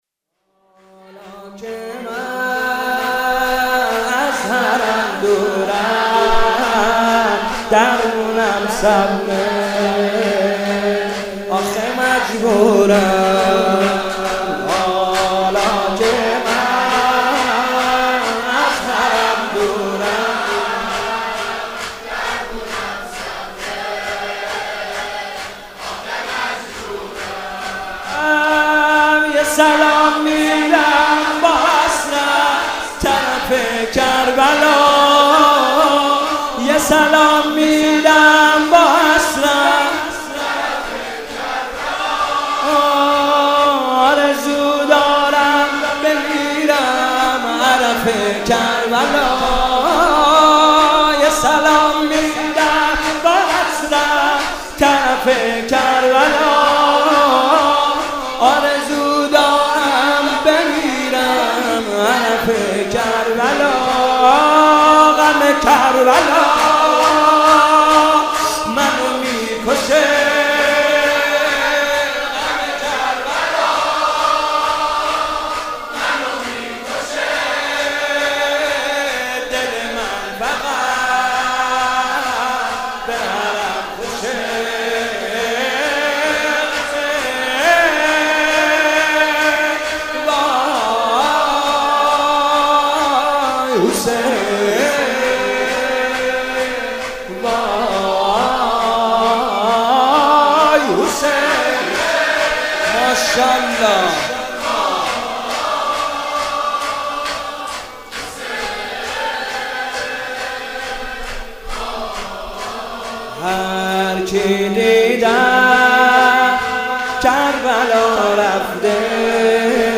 مناسبت : شب بیست و ششم رمضان
قالب : شور